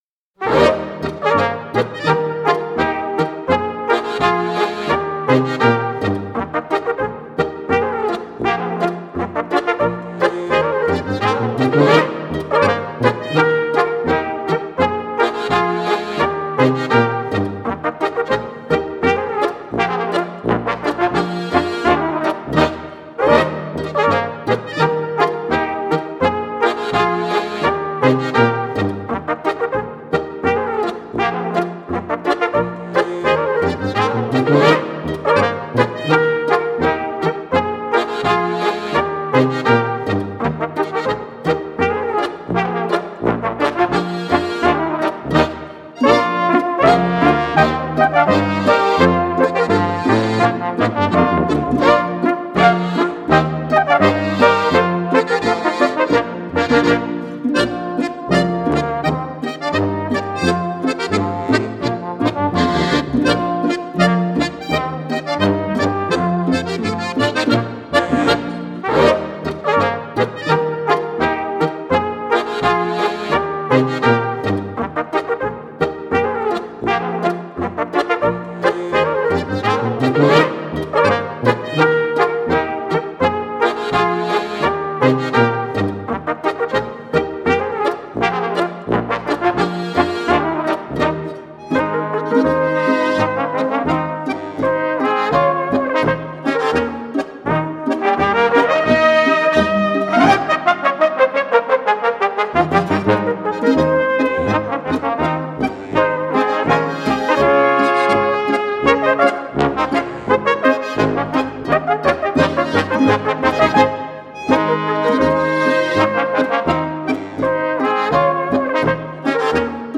Besetzung: Volksmusik Tanzlmusik
Klarinette in B
1. Flügelhorn in B
Posaune in B/C
Bass in C / Harmonika